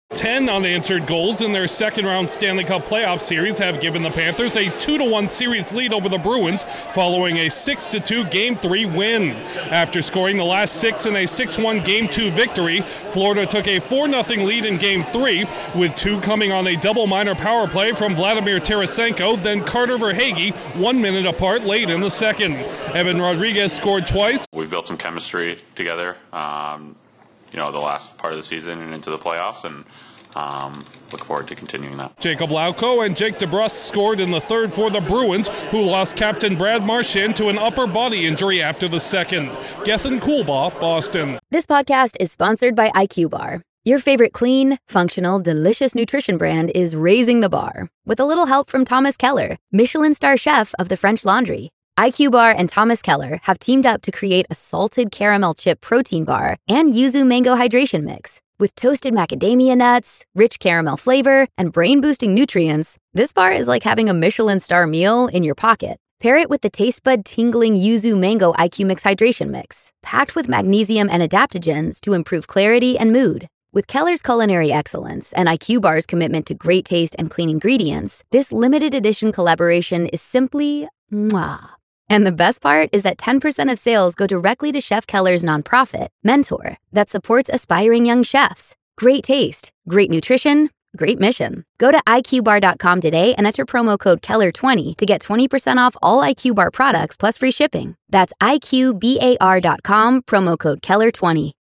The Panthers lean on special teams to hammer the Bruins. Correspondent